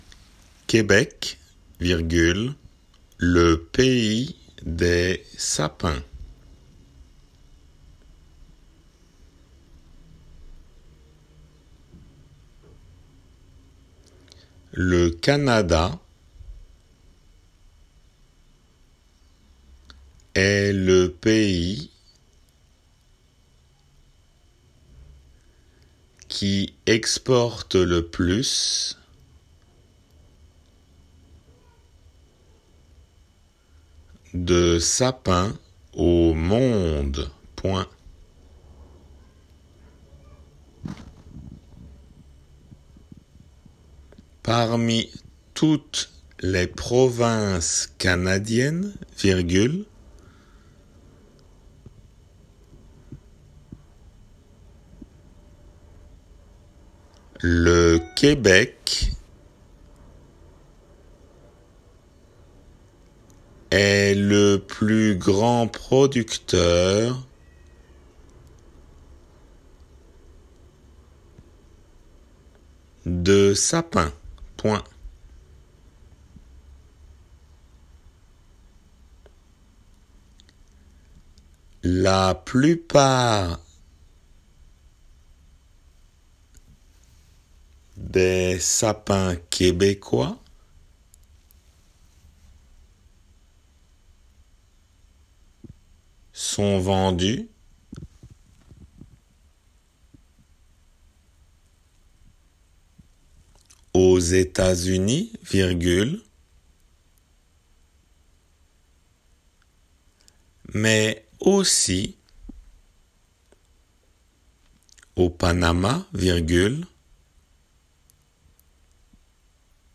デイクテの速さで